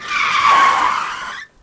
Skid_00.mp3